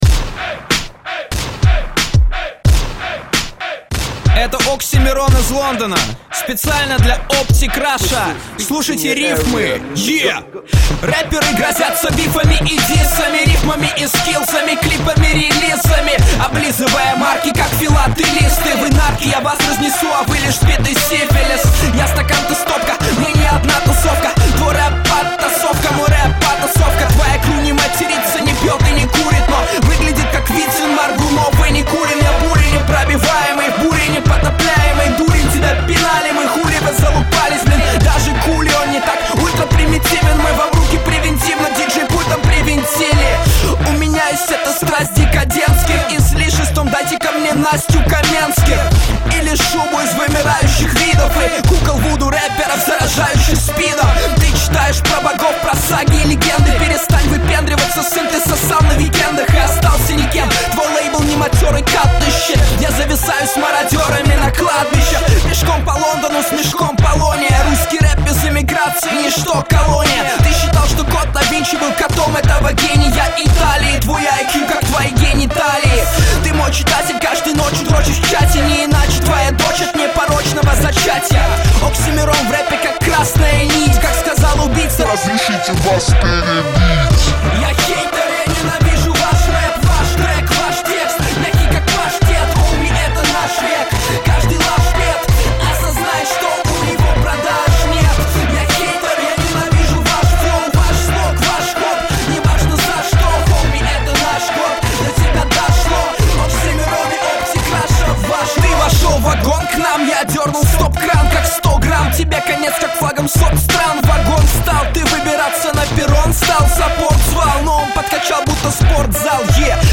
Категория: Русский Рэп/ Хип - Хоп